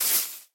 grass6.ogg